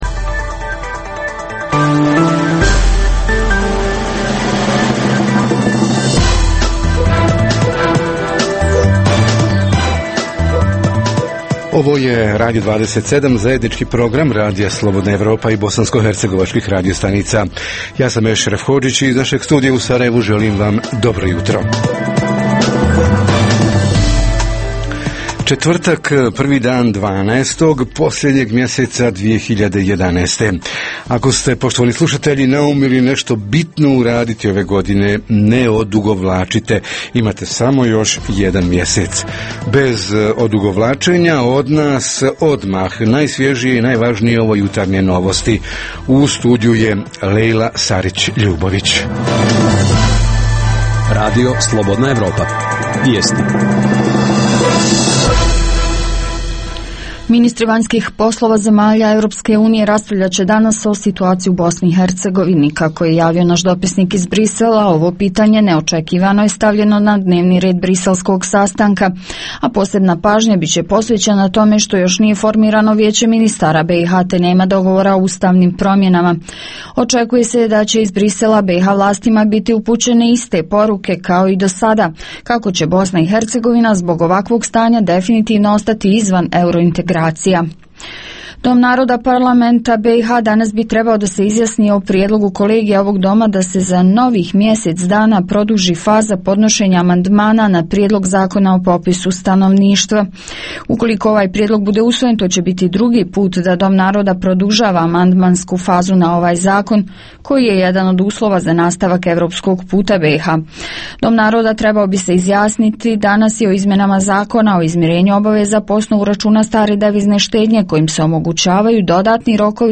Legalizacija bespravno sagrađenih stambenih objekata – koji su uslovi za legalizaciju, ko može, a ko ne može dobiti odobrenje, uz koje uslove i koliko to košta? Reporteri iz cijele BiH javljaju o najaktuelnijim događajima u njihovim sredinama.
Redovni sadržaji jutarnjeg programa za BiH su i vijesti i muzika.